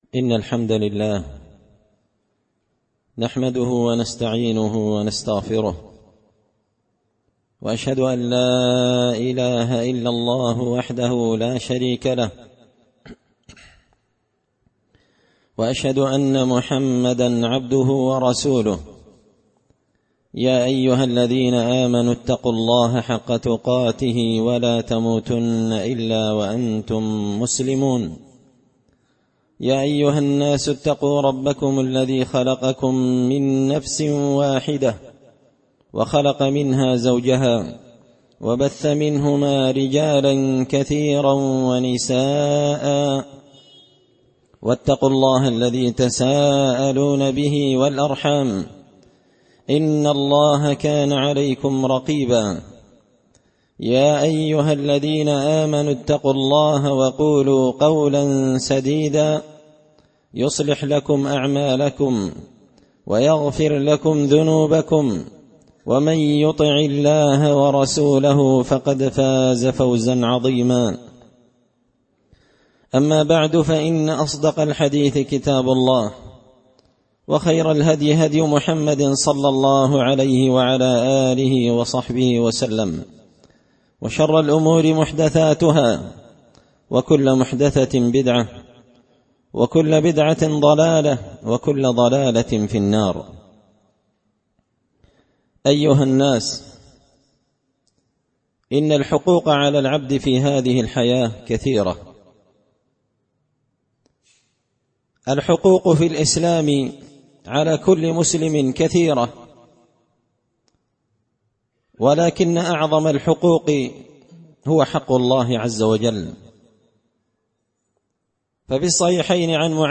خطبة جمعة بعنوان – حق الله تعالى الجزء الأول
دار الحديث بمسجد الفرقان ـ قشن ـ المهرة ـ اليمن